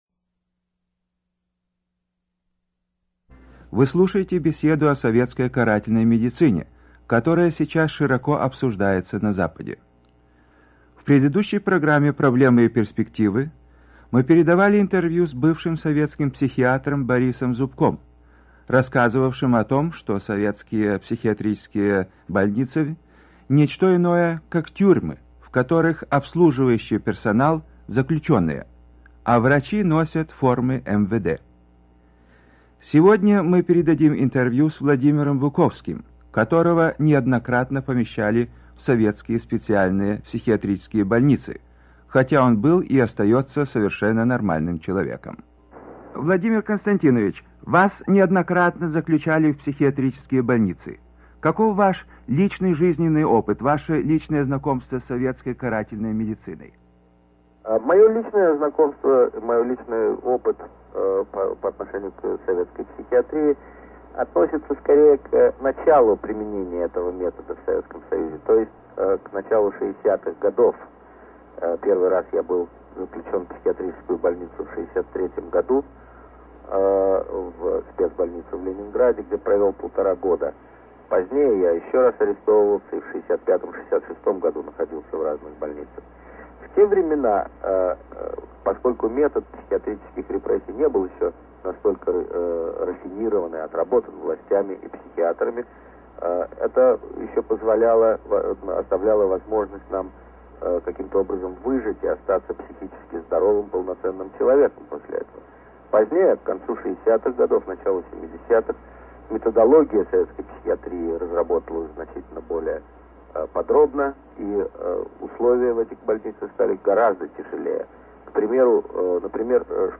В радиоинтервью Русской службе «Голоса Америки» известный писатель, правозащитник Владимир Константинович Буковский рассказывает о личном опыте пережитых им психиатрических репрессий в СССР в начале 1960-х годов.